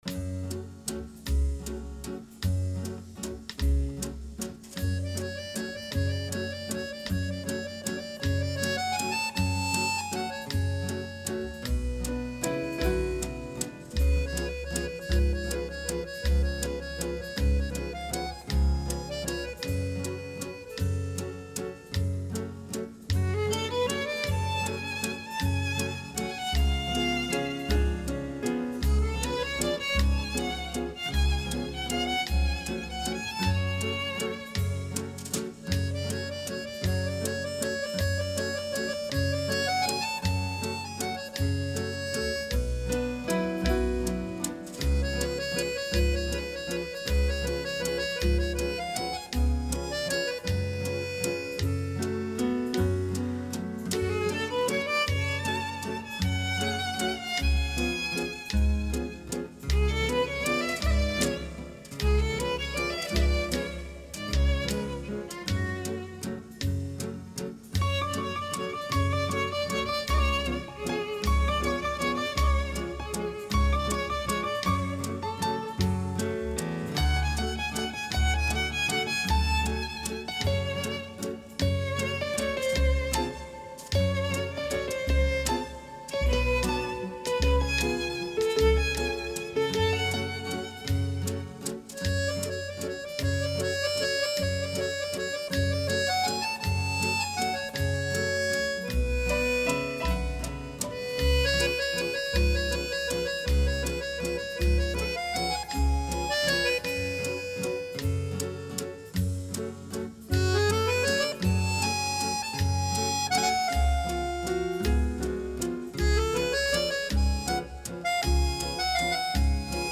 Η συνέντευξη πραγματοποιήθηκε την Τρίτη 21 Φεβρουαρίου 2022